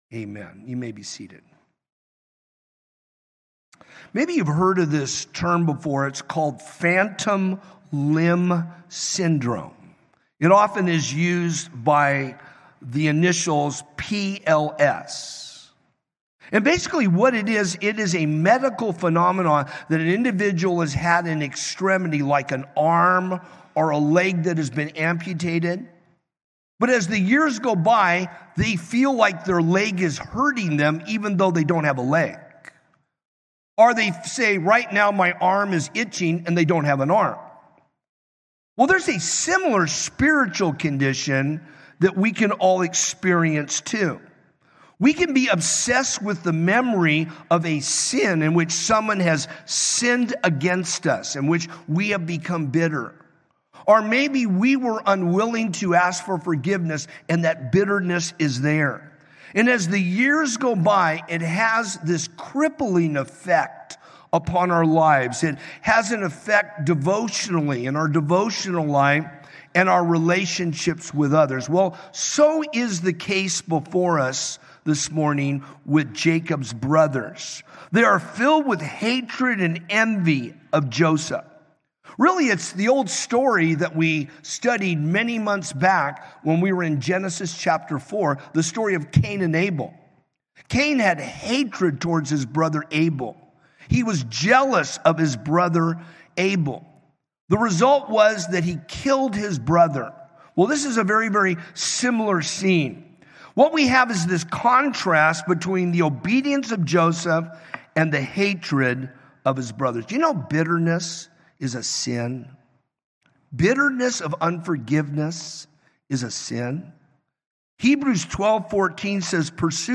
From Series: "Sunday Morning - 10:30"